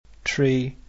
Irisch-Englisch
Die dentalen Frikative /θ/ und /ð/ werden im Irisch-Englischen zumeist durch die alveolaren Plosive /t/ bzw. /d/ ersetzt.
Es ist auditiv kein Unterschied zwischen 'tree' und 'three' zu vernehmen.
Bsp.: tree /tri:/ vs.